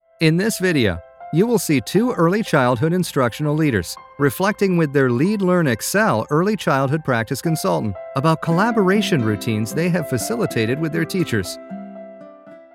Male
My voice has been described as honest, warm, soothing, articulate, relatable, sincere, natural, conversational, friendly, powerful, intelligent and "the guy next door."
E-Learning